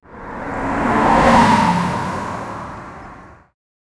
Free AI Sound Effect Generator
tuk-tuk-passing-in-road-bpyat7fv.wav